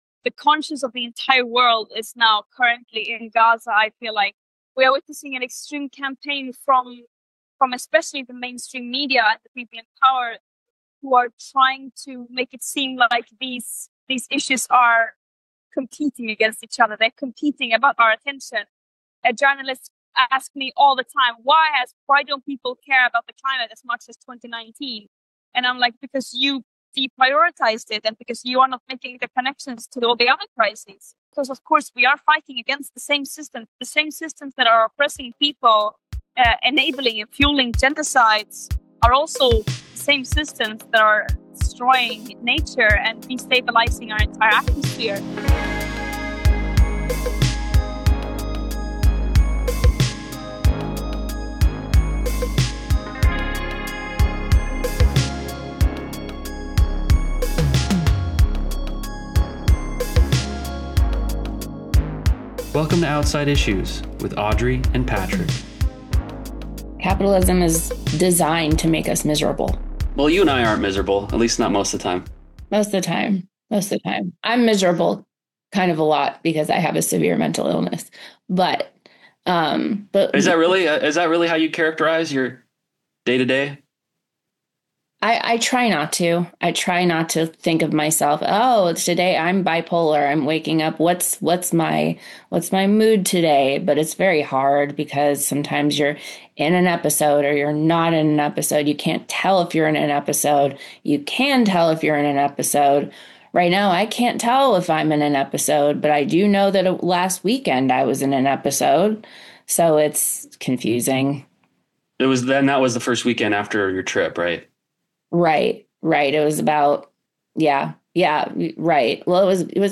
Opening quote by Greta Thunberg